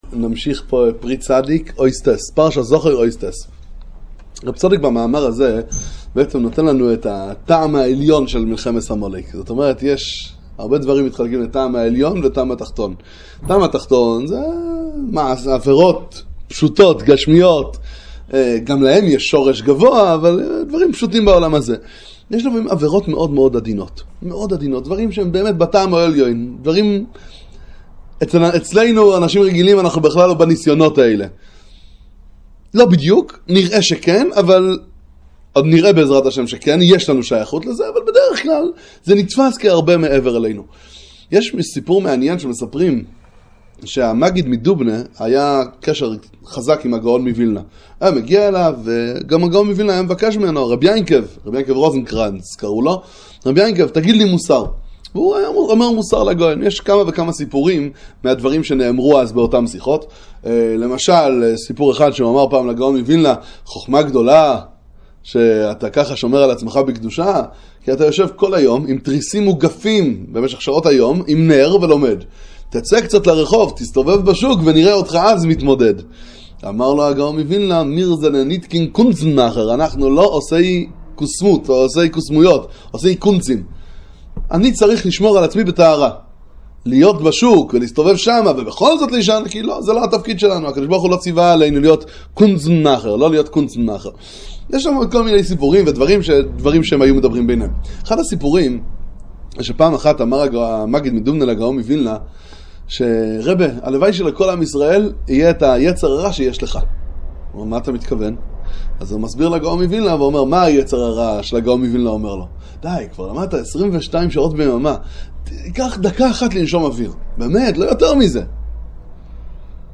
שיעורי תורה בספר פרי צדיק על פרשת השבוע